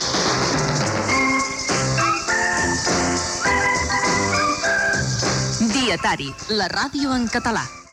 Identificació del programa
Informatiu